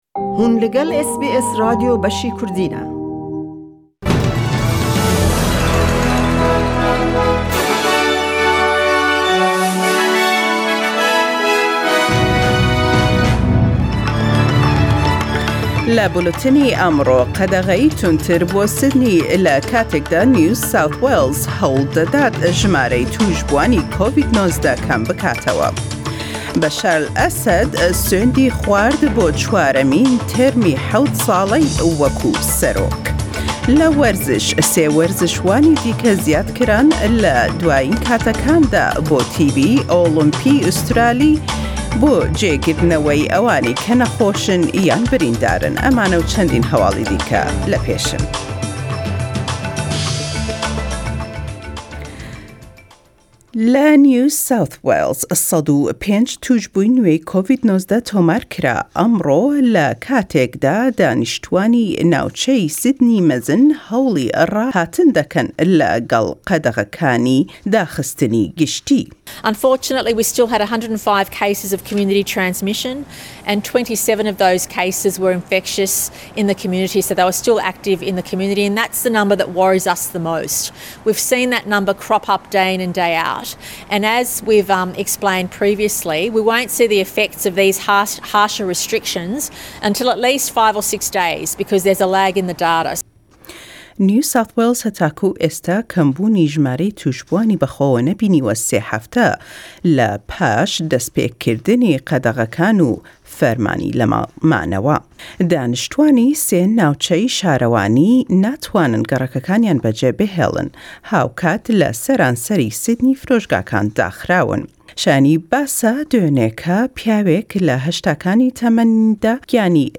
Weekend News 18 July 2021